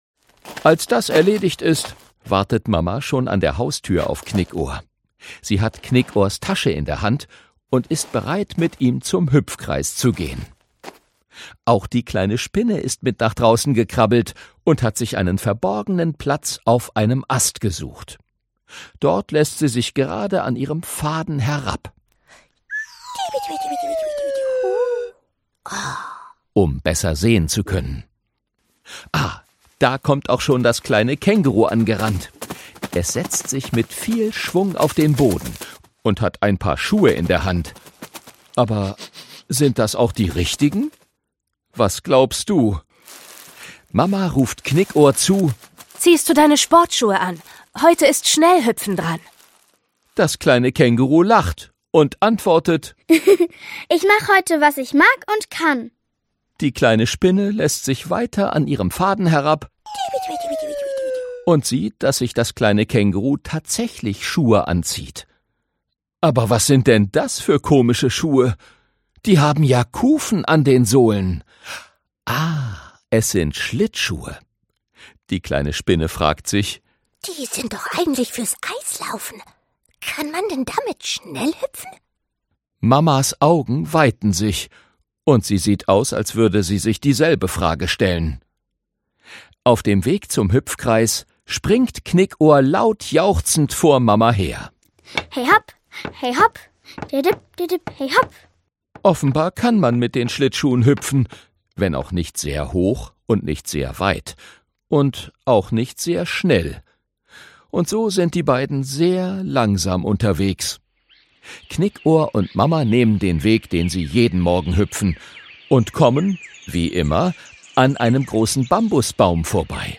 In exklusiver Hörbuchbearbeitung mit Känguru Knickohr-Song, kleinen Spielszenen und der humorvollen Lesung
Inszenierte Lesung mit Musik